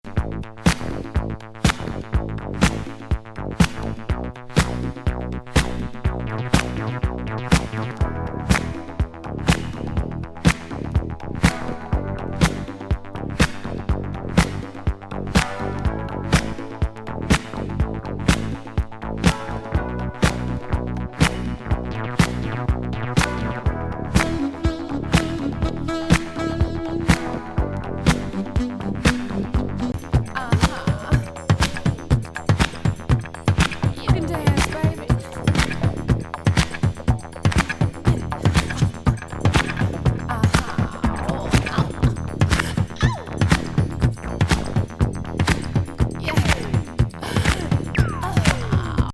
カナディアン・ディスコ・グループのミュインミュイン・ミュンヘン・ライク・シンセ
中盤からのコズミック・ジャングル・ブギーな展開も強力！